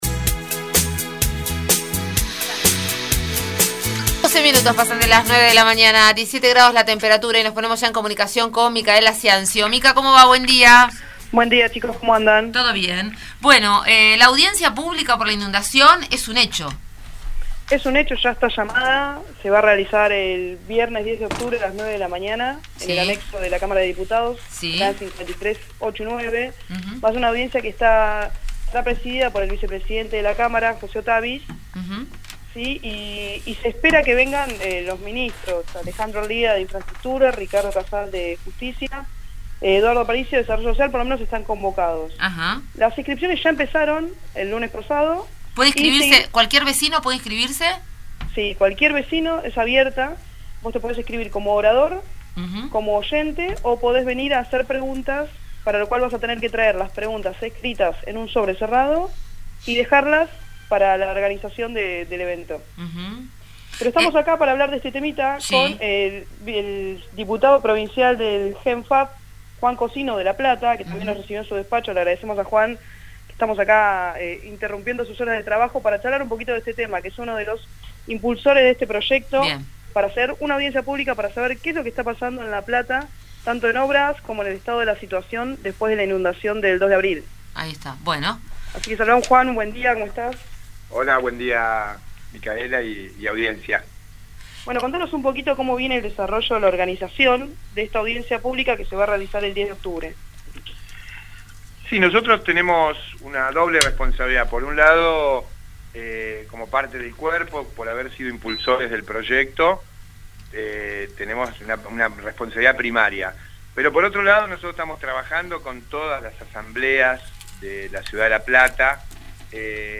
desde la legislatura En 221 Radio , el diputado provincial por La Plata Juan Cocino , del Frente Amplio Progresista , instó al intendente Pablo Bruera y al gobernador Daniel Scioli a participar de la audiencia pública sobre la inundación del 2 de abril de 2013 convocada por la Cámara de Diputados bonaerense.